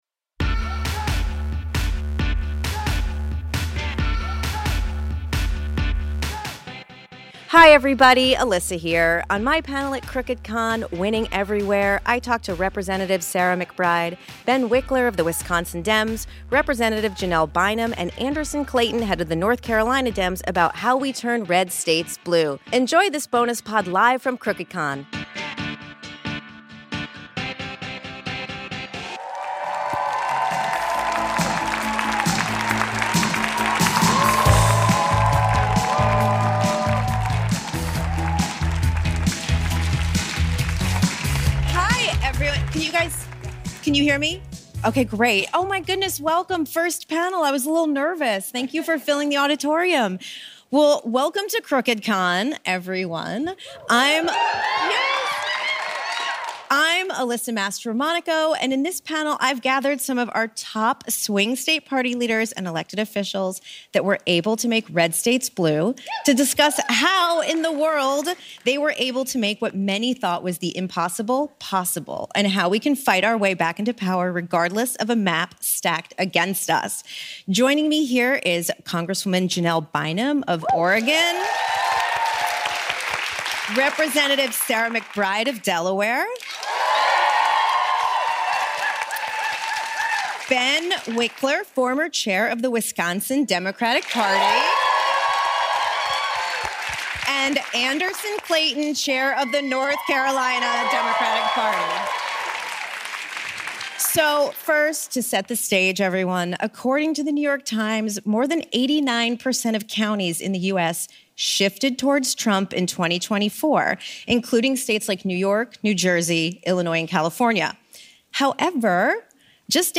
How can Democrats win everywhere? (Crooked Con)
We need a new strategy that works as well in supposedly safe states like New Jersey as it does in tossups like North Carolina and Wisconsin. Alyssa Mastromonaco hosts Rep. Sarah McBride (D–DE), Rep. Janelle Bynum (D–OR), Anderson Clayton, chair of the North Carolina Democratic Party, and Ben Wikler, former chair of the Wisconsin Democratic Party to talk about how we can fight our way back to power everywhere on the map.